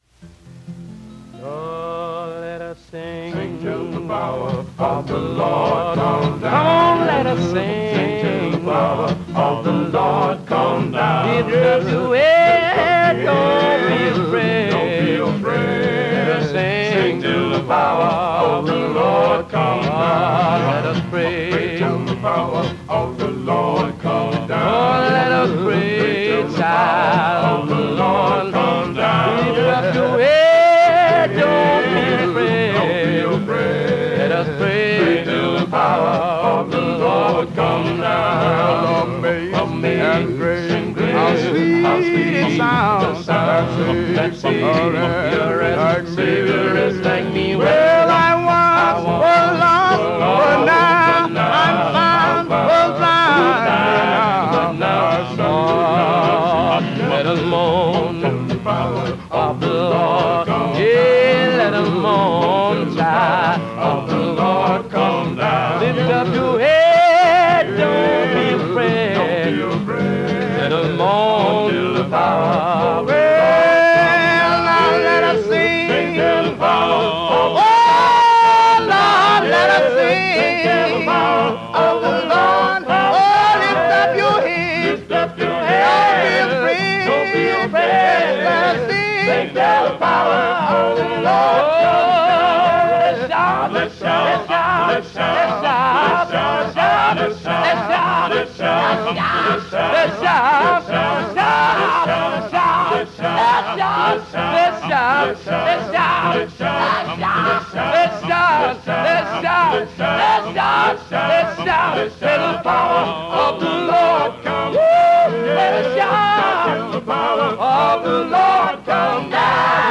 Christian, gospel music, podcast, songs